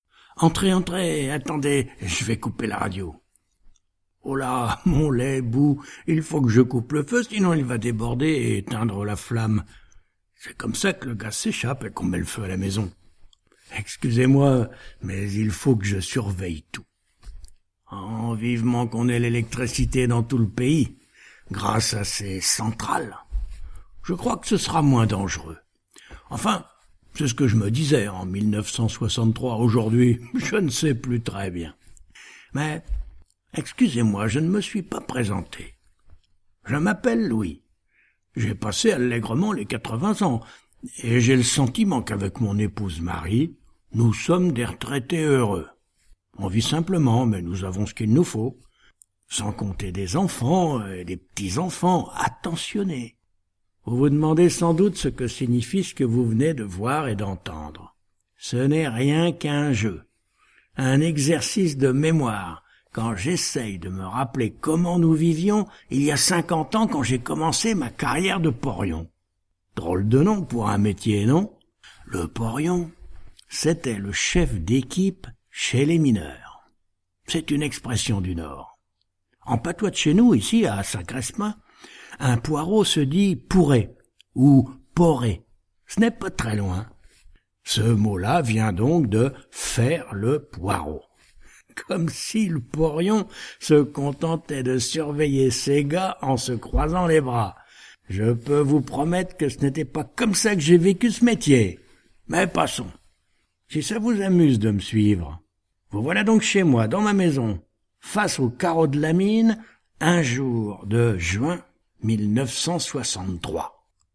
voix naturelle, nerveuse, peut être jouée, idéale pour livres audios et voice over
Sprechprobe: Werbung (Muttersprache):